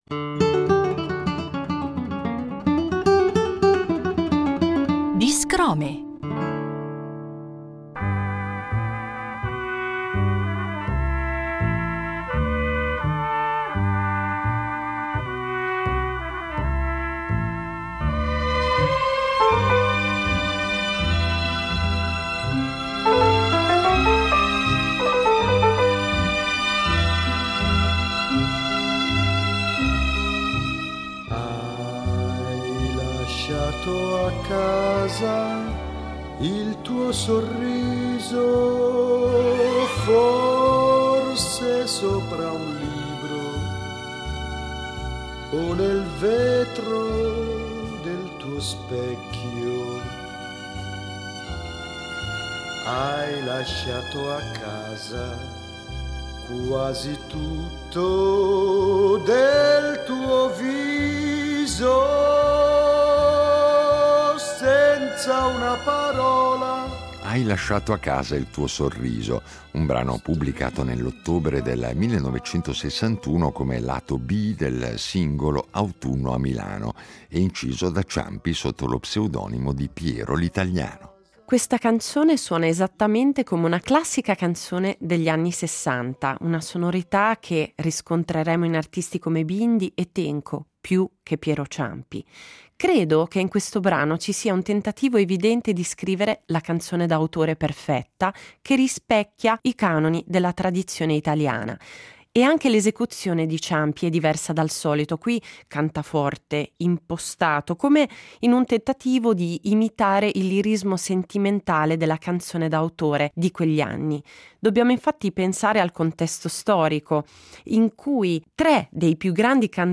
Ogni puntata di Non siamo tutti eroi segue una precisa drammaturgia radiofonica.
L’apertura propone un frammento originale delle storiche incisioni di Piero Ciampi, come gesto di memoria e invocazione d’archivio.
In chiusura, l’ascolto integrale della rilettura musicale tratta dal disco Non siamo tutti eroi, interpretata da un ensemble interamente femminile.